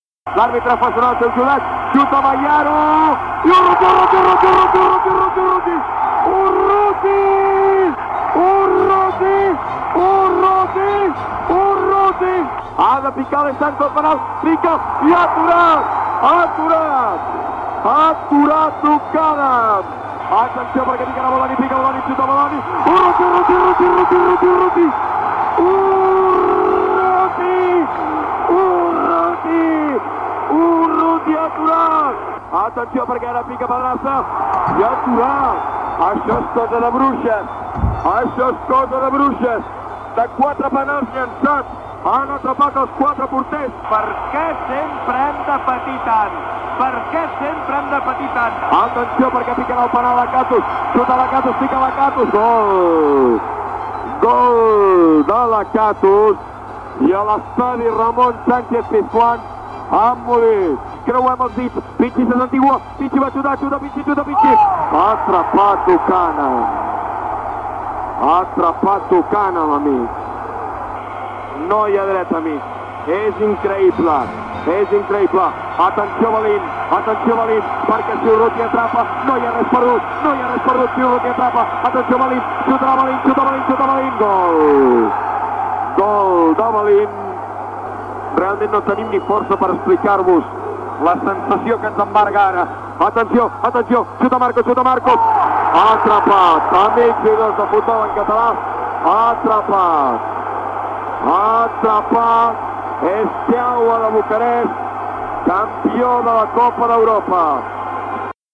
Narració del llançament dels penalties en el desempat de la final de la Copa d'Europa masculina de futbol entre el Fútbol Club Barcelona i Steaua de Bucarest, a l'Estadio Ramón Sánchez Pizjuán, de Sevilla.
Esportiu